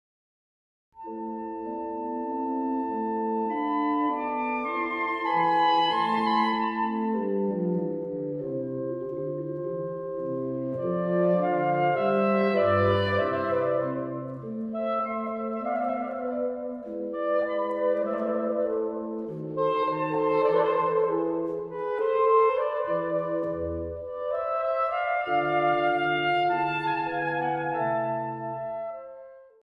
Largo 3:05